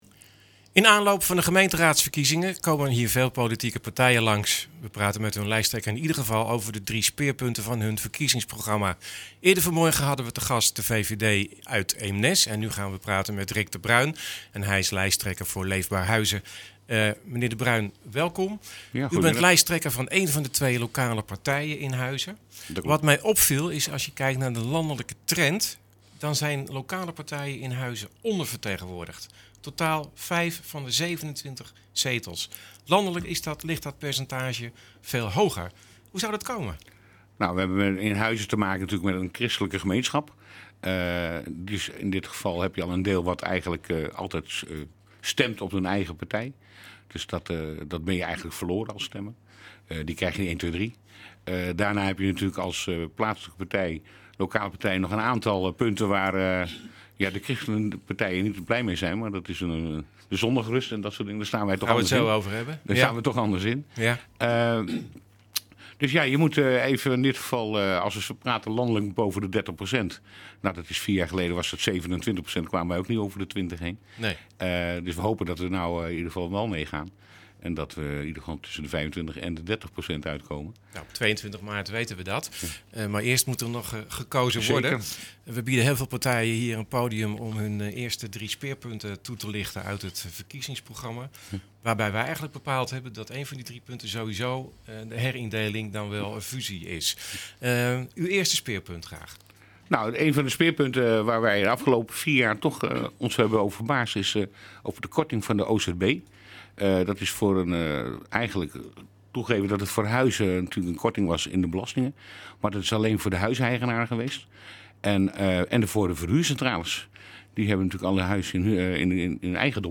In aanloop naar de gemeenteraadsverkiezingen komen er hier veel politieke partijen langs. We praten met hun lijsttrekker in iedere geval over de drie speerpunten van hun verkiezingsprogramma.